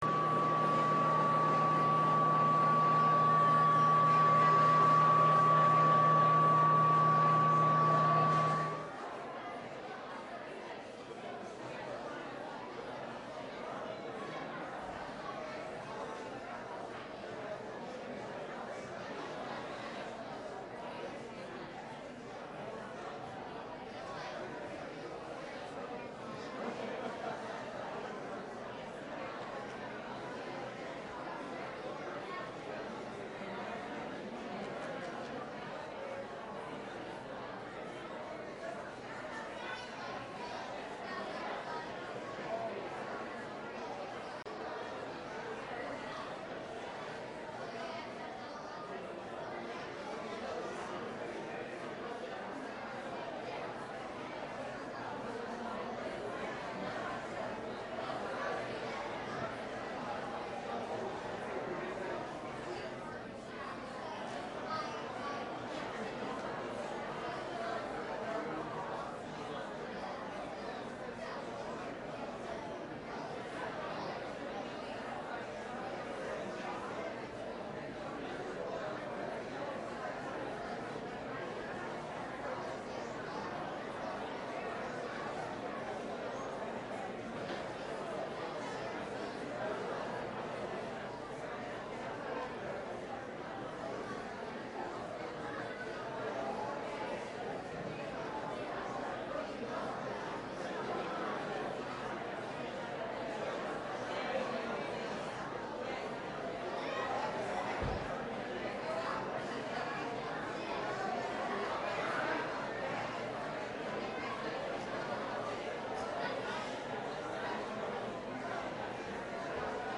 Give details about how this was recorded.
This sermon was given at the Lake Junaluska, North Carolina 2017 Feast site.